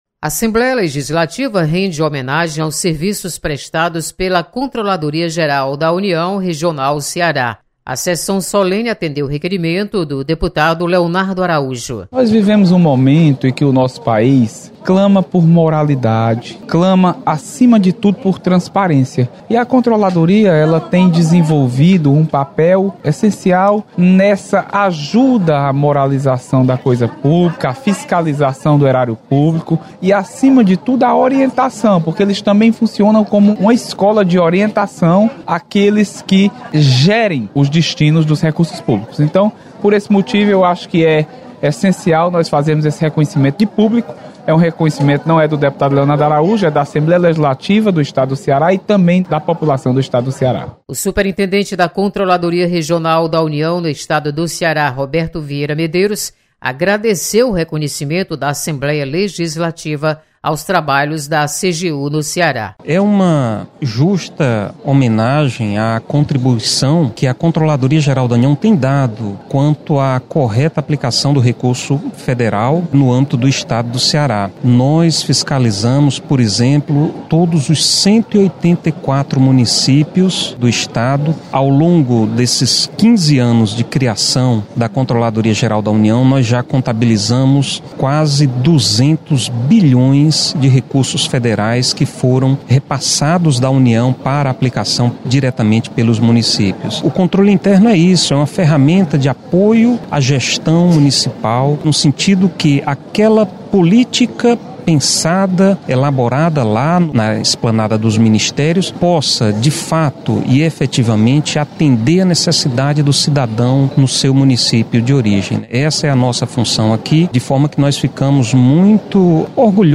Você está aqui: Início Comunicação Rádio FM Assembleia Notícias Homenagem